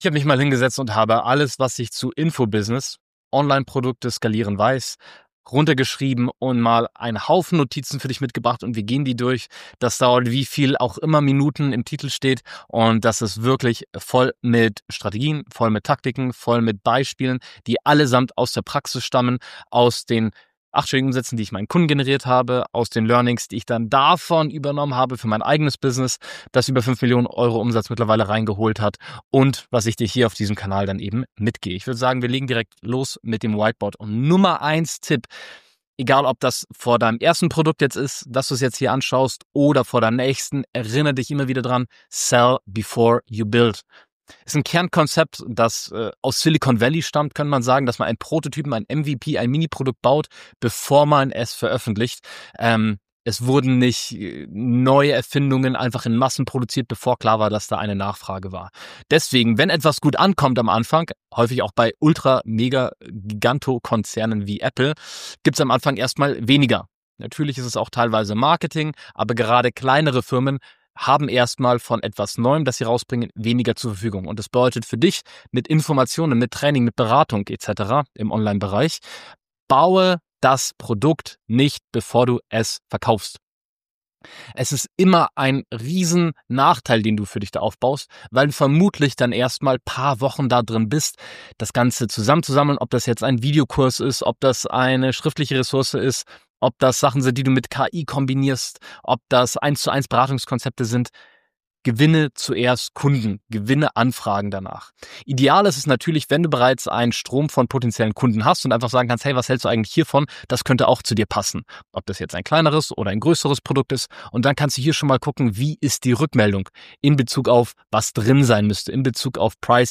In dieser umfassenden Lecture geht es um die Strategien und Taktiken, die zur Skalierung eines Infobusiness und erfolgreicher Online-Produkte beitragen.